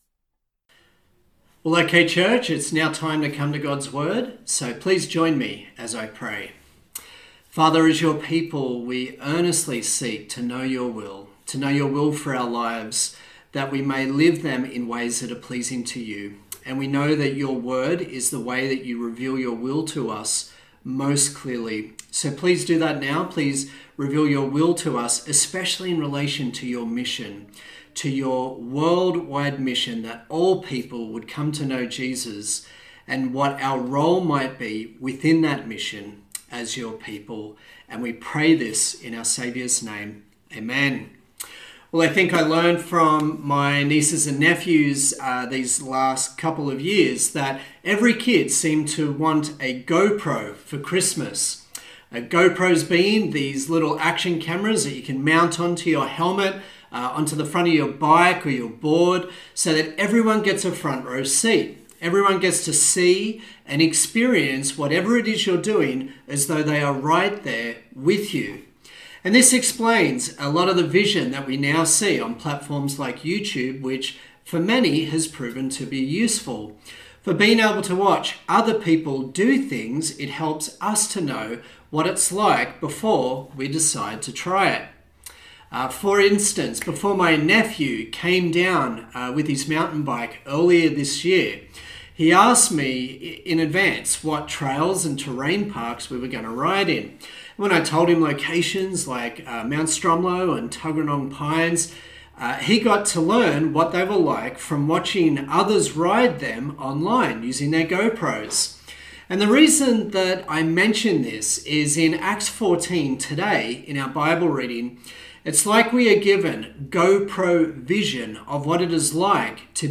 Acts Passage: Acts 14:8-28 Service Type: Sunday Morning